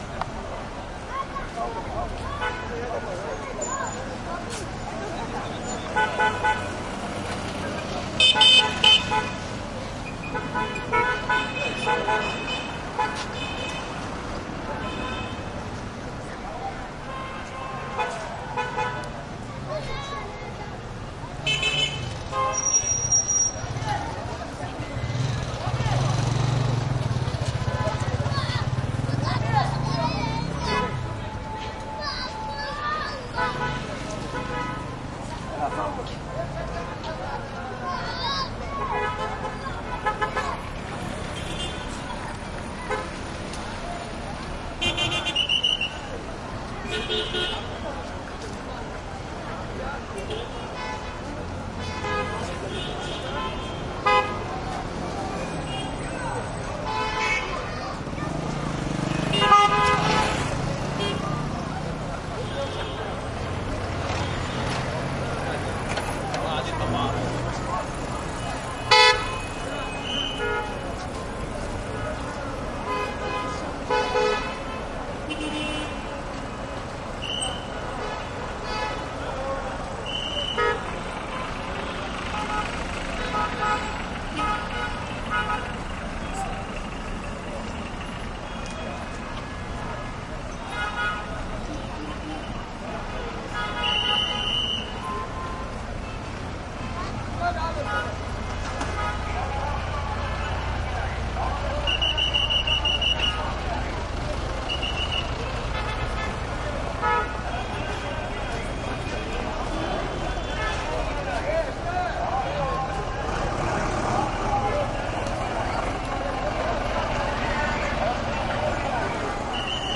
加沙 " 交通 中型 中东 交叉口 市场 入口 人们 孩子 喇叭 悸动的汽车和哨子 警察 +PA
描述：交通媒介中东十字路口市场入口人儿童小孩喇叭鸣笛汽车和口哨警察+ PA街头声音附近呼应加沙2016.wav
Tag: 市场 路口 交通 中东 城市